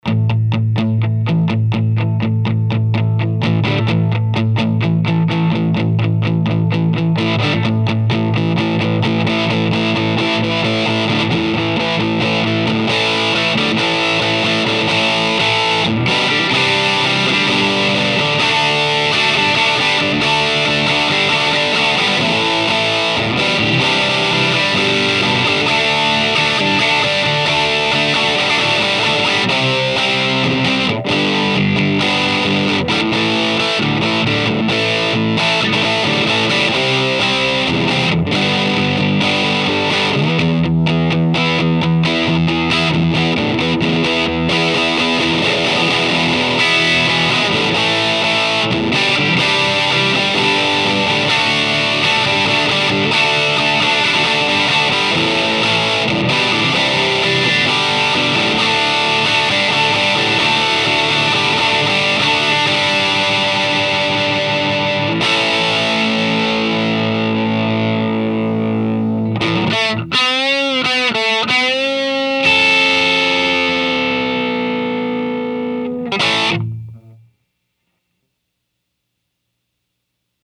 • I plugged directly into my Aracom VRX22, which then fed into my Aracom PRX150-Pro, then out to a custom 1 X 12 with a Jensen P12N
• The amp was in the drive channel with master at 6, volume (gain) at 6, and tone at 6 (the tone on this amp adds a little gain as well as an edge)
• The PRX150-Pro was set at maximum attenuation
• Volume-wise, this was talking conversation level!!!
• No EQ was applied to the guitar – what you’re hearing is the raw tone.